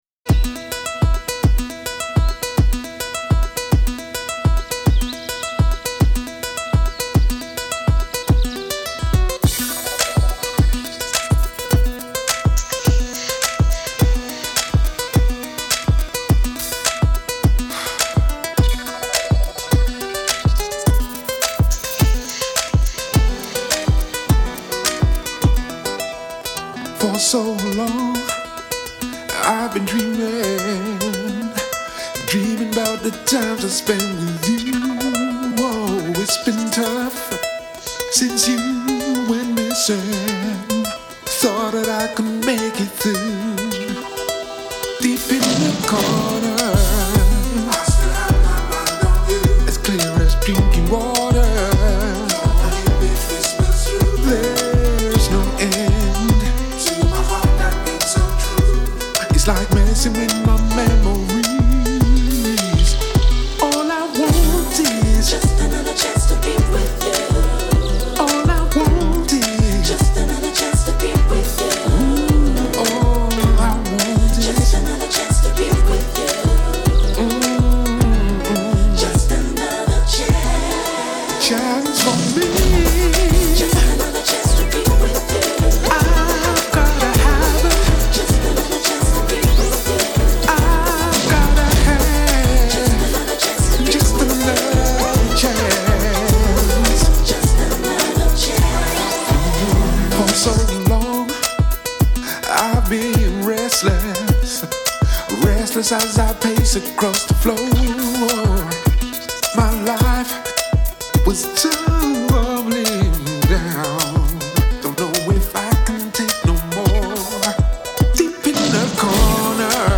soul , uptempo